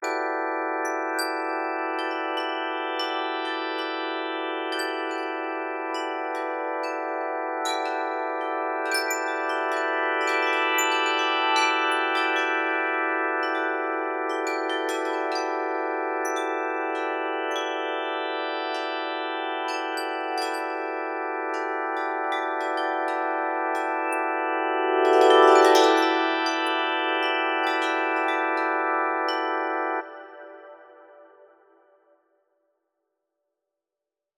Sweeping around clockwise from the top of the image, the scan encounters Chandra’s X-rays and plays them as single-note wind chimes.
sonify10_cena_xray_chandra.wav